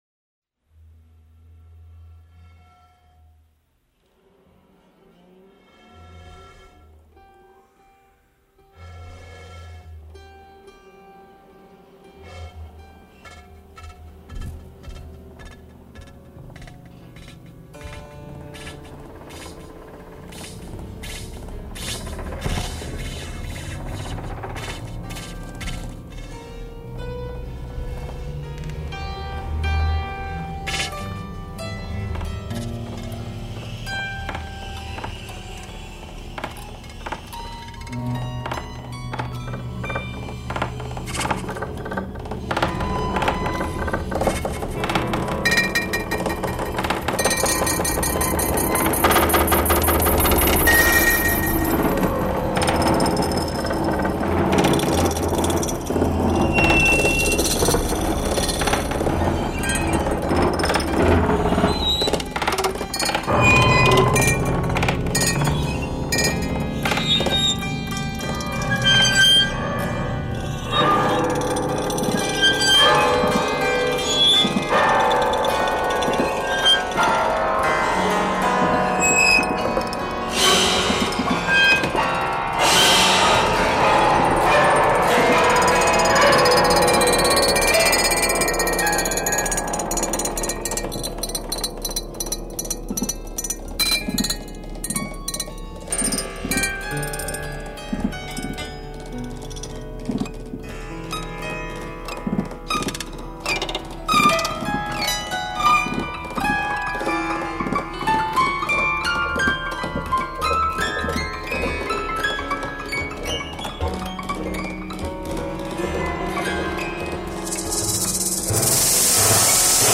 Recorded: Wümme, 1970-1971